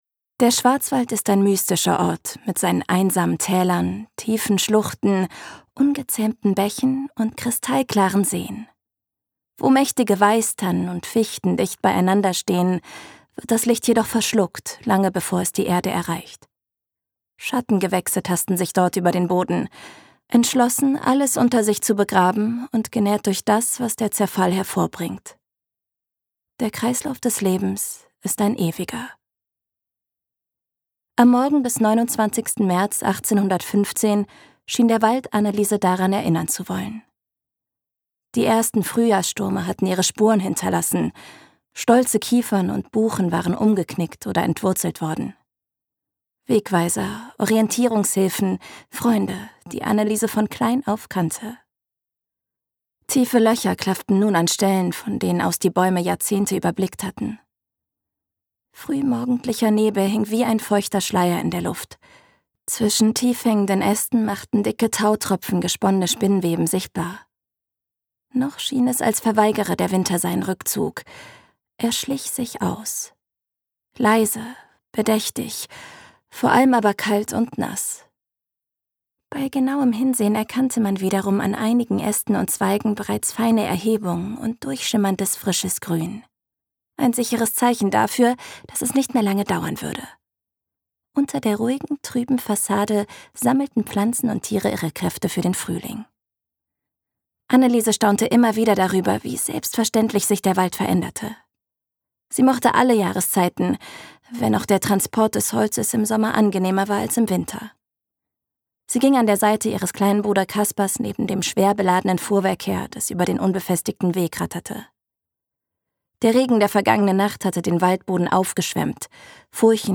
Details zur Sprecherin